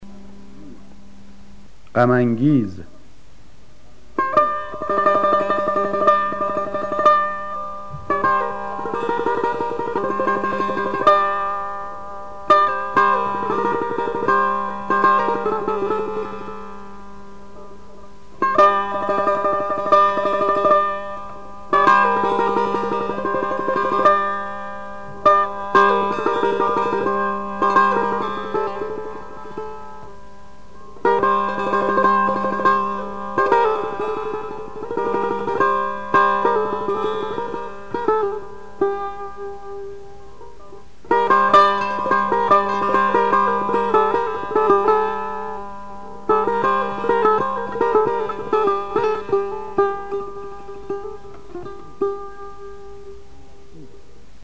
آواز دشتی ردیف میرزا عبدالله سه تار
غم انگیر، آواز دشتی